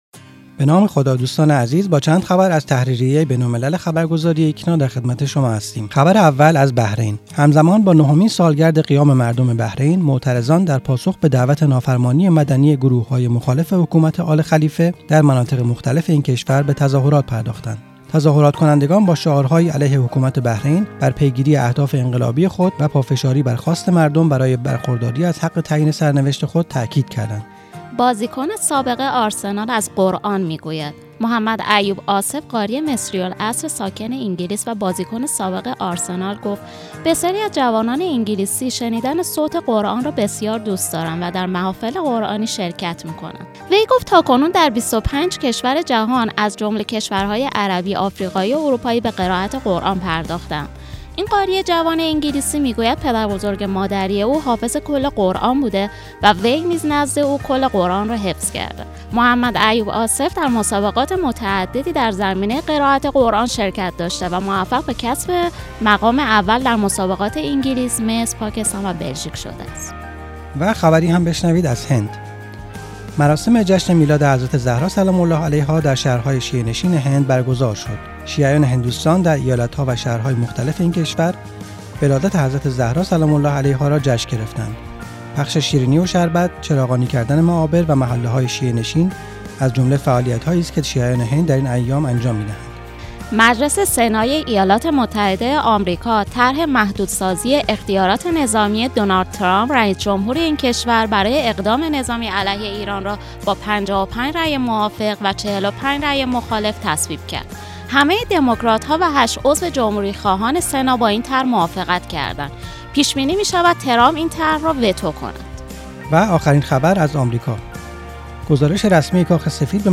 صوت| مروری بر اخبار جهان اسلام 26 بهمن‌ماه